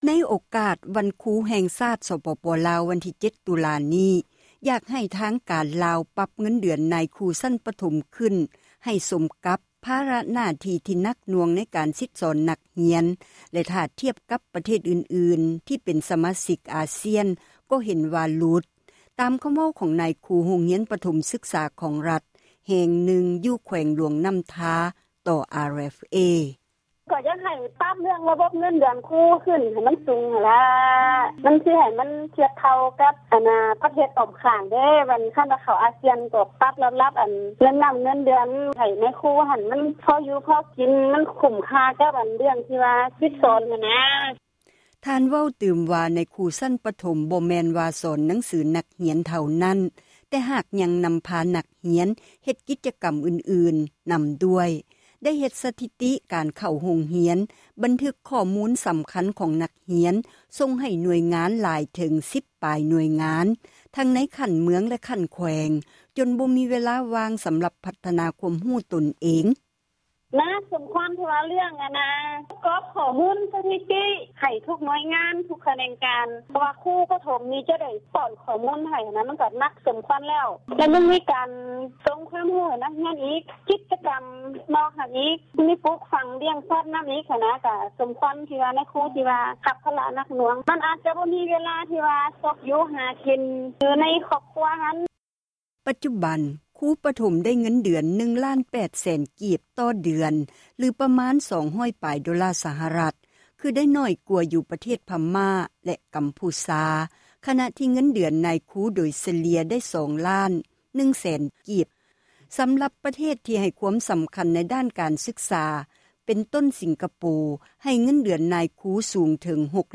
ໃນໂອກາດ ວັນຄຣູ ແຫ່ງຊາດ ສປປລາວ ວັນທີ 7 ຕຸລາ ນີ້ ນາຍຄຣູ ໂຮງຮຽນ ປະຖົມ ສຶກສາ ຂອງຣັຖ ແຫ່ງນຶ່ງ ຢູ່ແຂວງ ຫຼວງນ້ຳທາ ເວົ້າຕໍ່ ວິທຍຸ ເອເຊັຽເສຣີ ວ່າ ຢາກໃຫ້ ທາງການລາວ ປັບເງິນ ເດືອນ ຂອງ ນາຍຄຣູ ຊັ້ນປະຖົມ ຂຶ້ນໃຫ້ສົມ ກັບພາລະໜ້າທີ່ ທີ່ໜັກໜ່ວງ ໃນການ ສິດສອນ ນັກຮຽນ ແລະ ຖ້າທຽບໃສ່ ປະເທດອື່ນໆ ທີ່ເປັນ ສະມາຊິກ ອາຊຽນ ກໍເຫັນວ່າ ຫຼຸດໂຕນ ກັນຫຼາຍ: